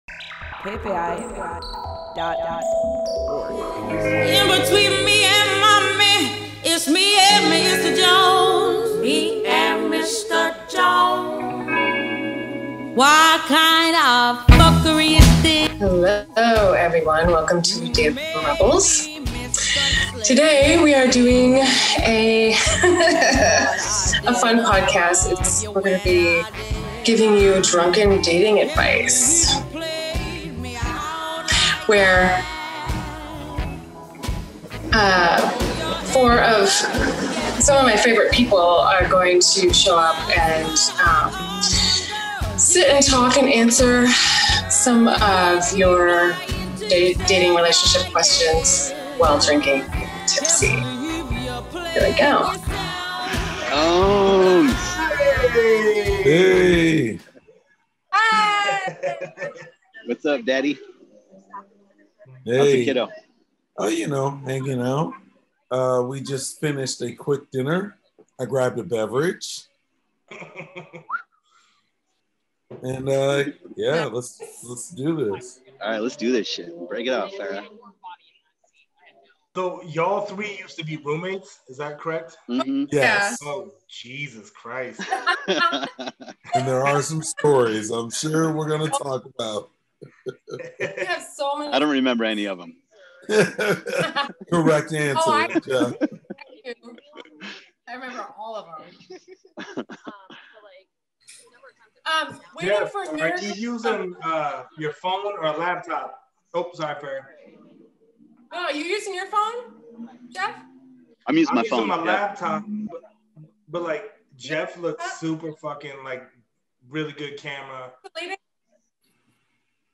to answer your dating questions on Zoom.